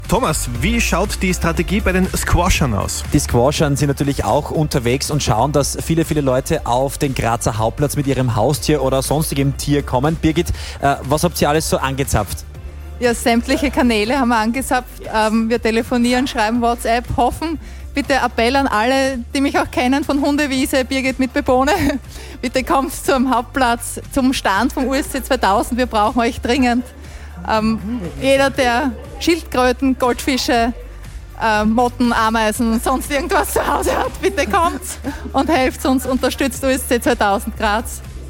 Lustig war es trotzdem und Squash war wieder einmal in dem Medium Radio zu hören.